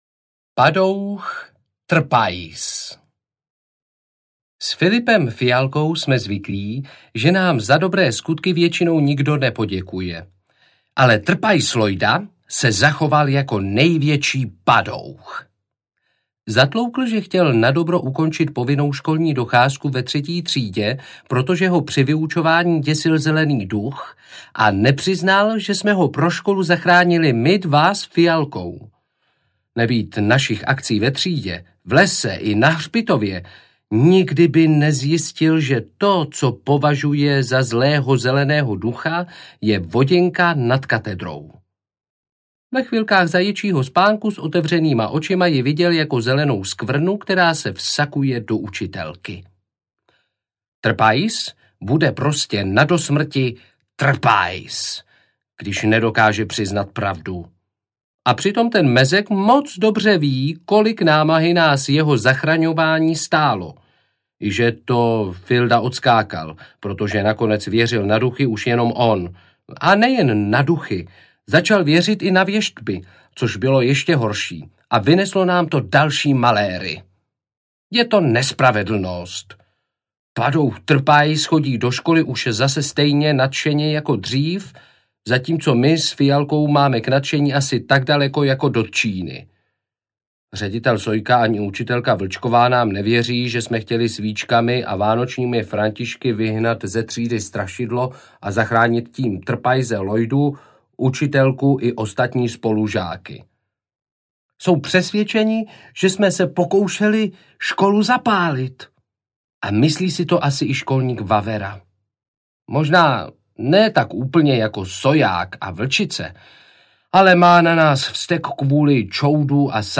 Kouzláci audiokniha
Ukázka z knihy